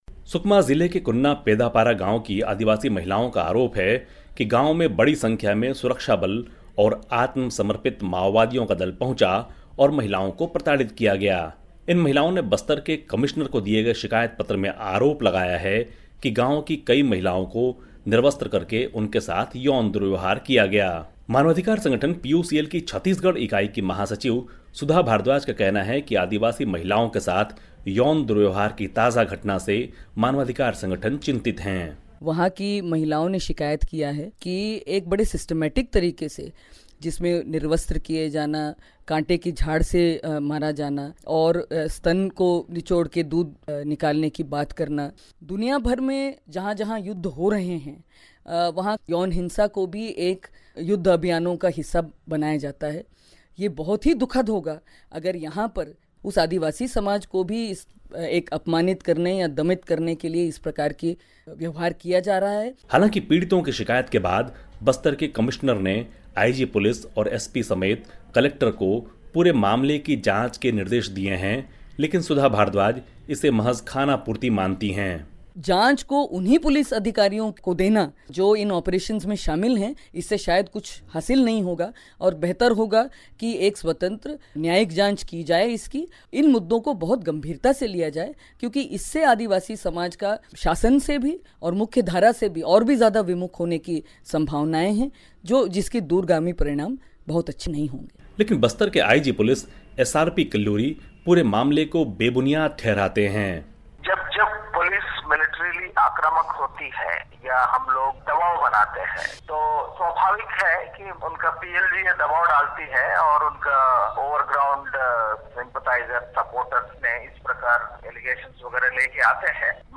ख़ास ख़बर